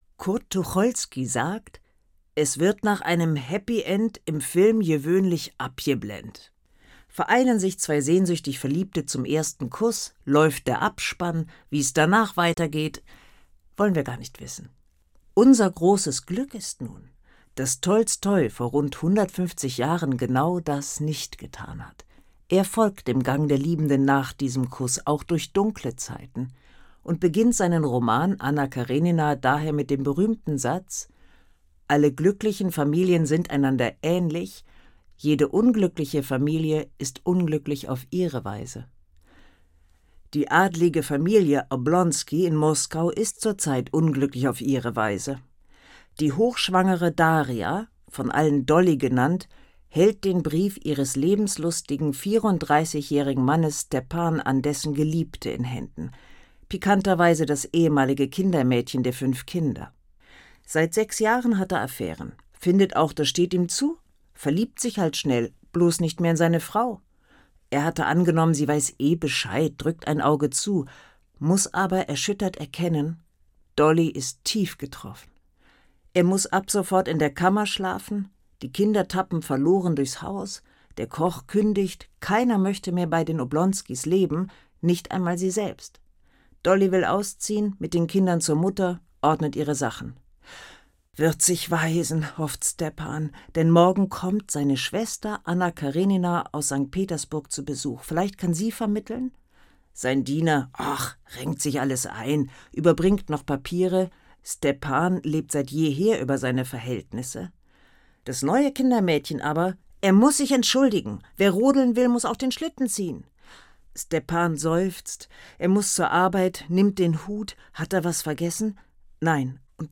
horprobe_anna_karenina.mp3